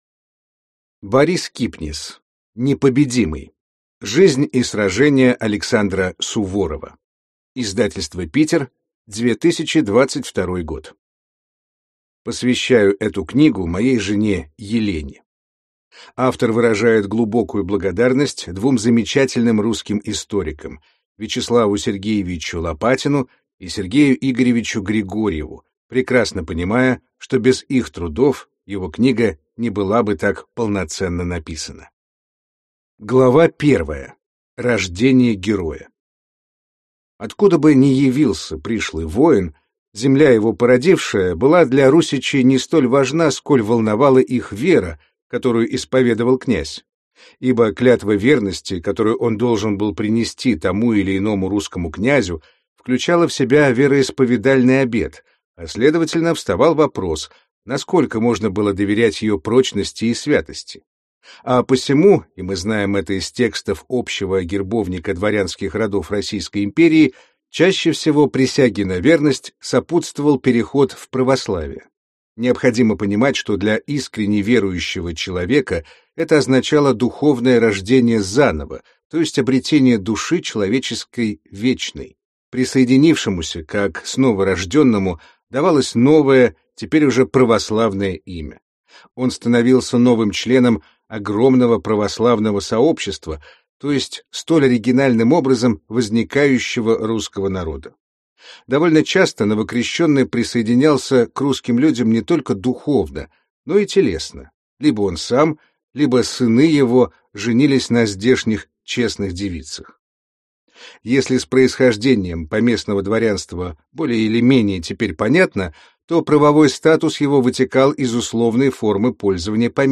Аудиокнига Непобедимый. Жизнь и сражения Александра Суворова | Библиотека аудиокниг